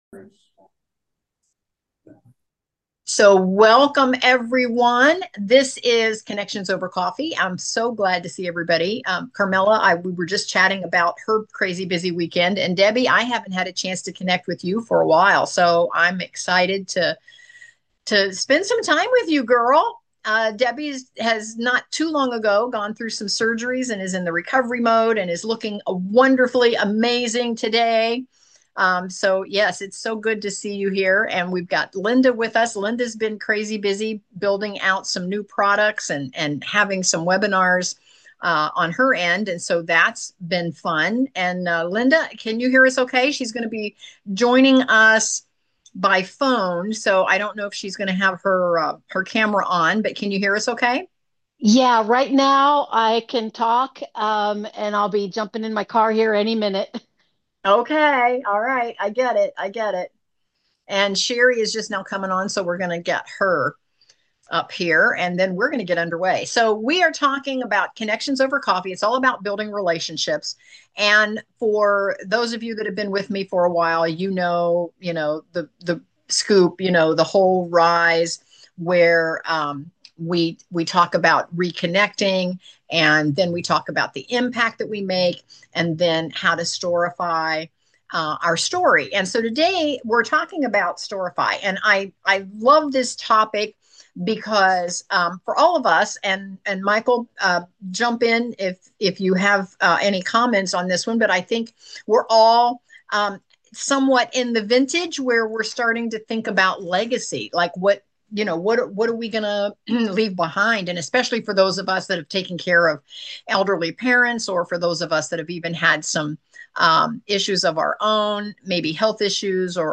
a virtual party on Zoom that's all about connections, networking, laughter, and some good vibes